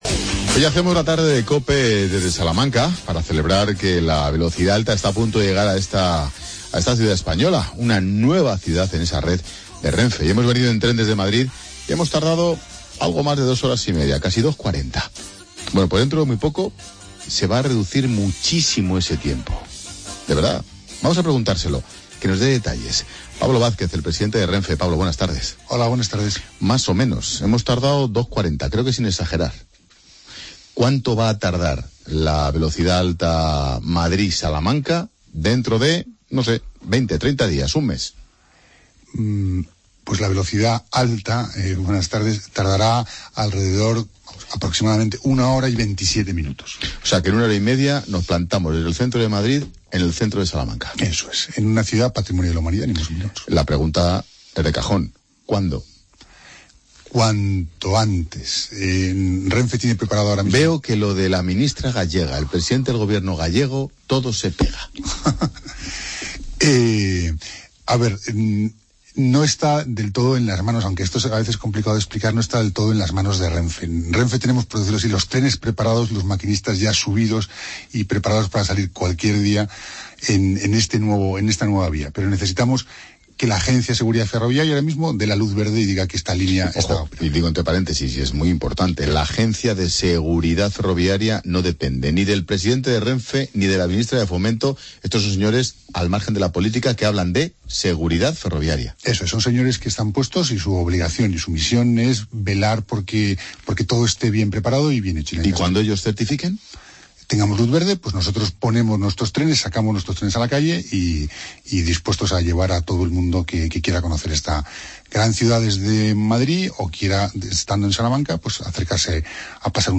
El presidente de RENFE nos acompaña en La Tarde desde Salamanca para hablarnos del nuevo servicio de Ave que unirá la ciudad con Madrid y que está a punto de inaugurarse.